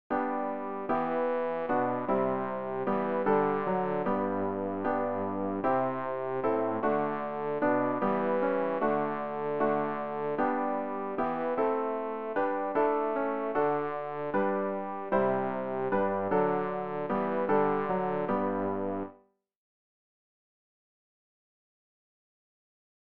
rg-631-nun-lasst-uns-gott-bass.mp3